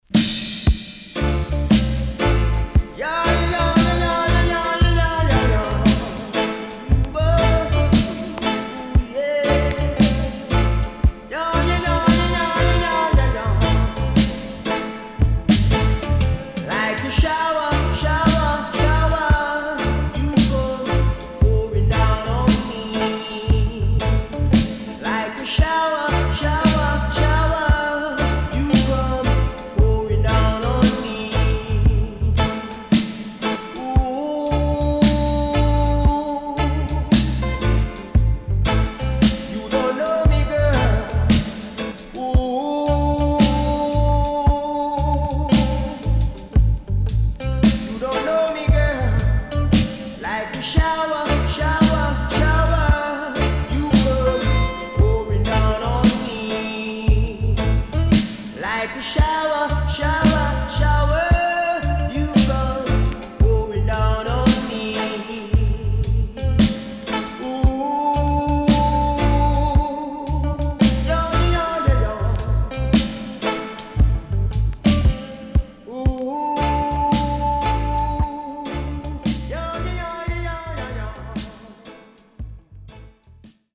SIDE A:所々チリノイズ入ります。